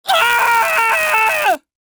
Screams Male 02
Screams Male 02.wav